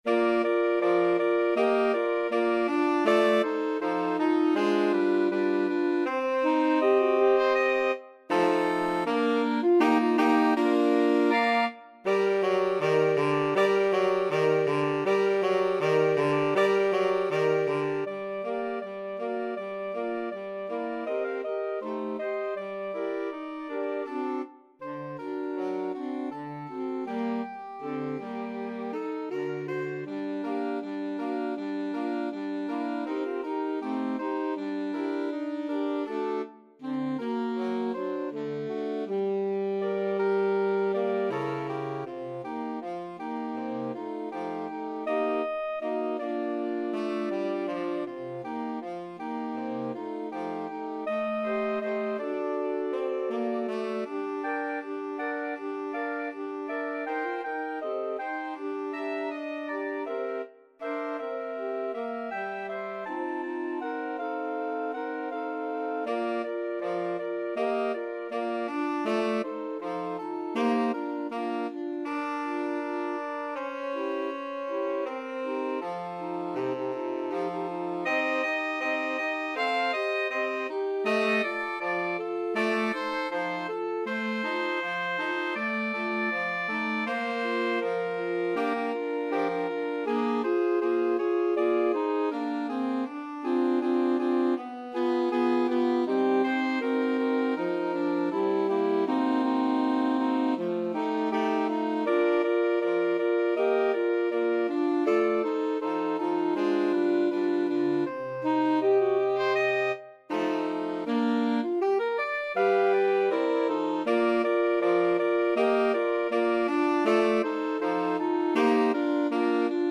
Saxophone Quartet version
2/2 (View more 2/2 Music)
~ = 160 Moderato
Pop (View more Pop Saxophone Quartet Music)